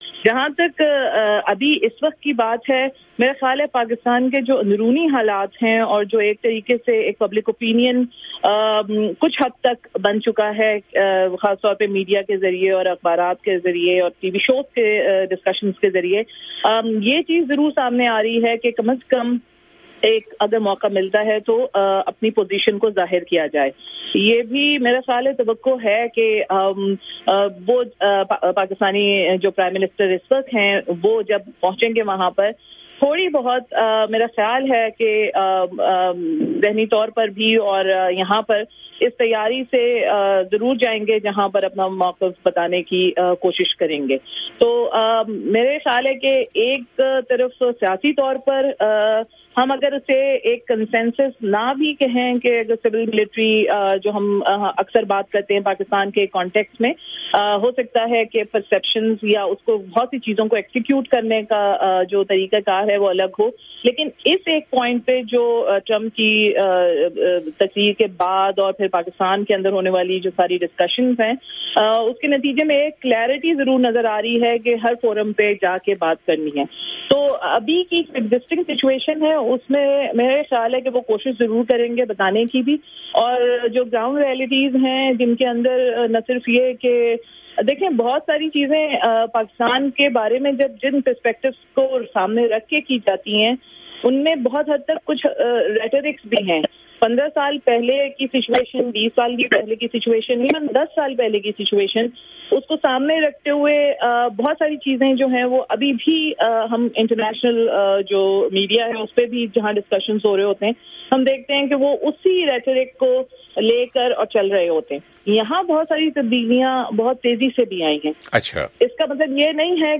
وائس آف امریکہ کے پروگرام 'جہاں رنگ' میں اس موضوع پر ہونے والی گفتگو